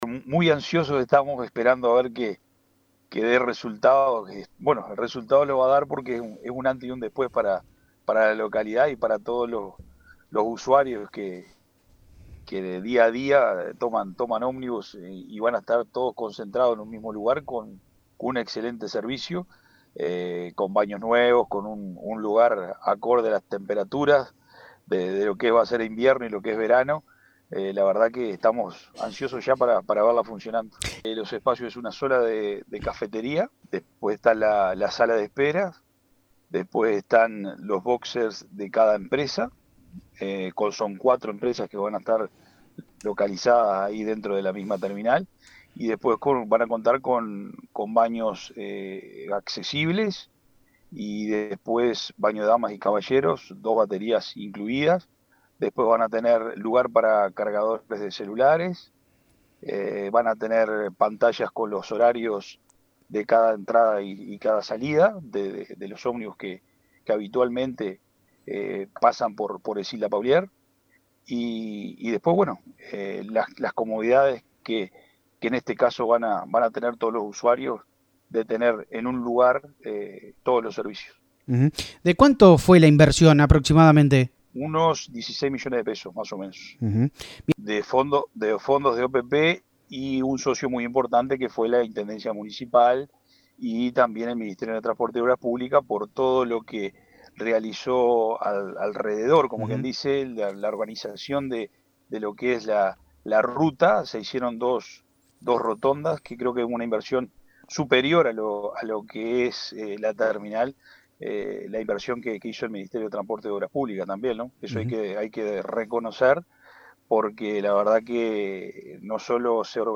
El alcalde de Ecilda Paullier, Ignacio Mesa, explicó las características de la nueva infraestructura y los servicios que tendrá para los usuarios.